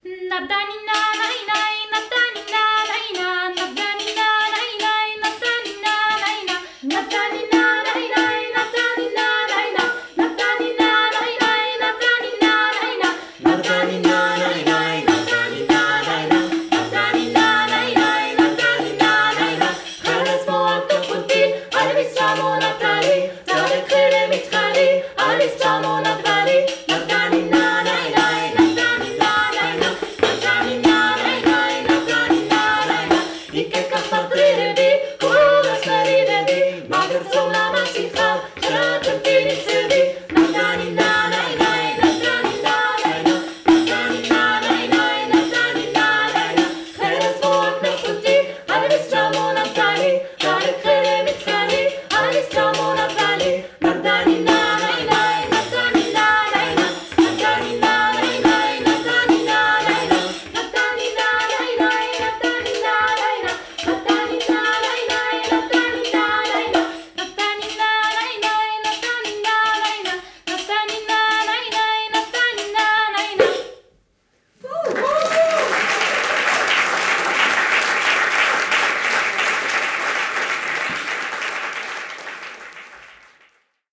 eine Familie und ein A Capella-Trio. Wir singen dreistimmige Lieder aus aller Welt, z.B. Griechenland, Frankreich, Georgien, Irland, dem Alpenland u.v.m. Unsere Verbindung untereinander und die Liebe zum Gesang ist für uns selbst immer wieder eine große Freude und wirkt sich bei unseren Konzerten auf den ganzen Raum inklusive unsere Zuhörer aus.
Der Klangcharakter des Offenen Vokalensembles ist direkt, authentisch und lebendig, ausgezeichnet durch innere Verbundenheit und Stimmigkeit.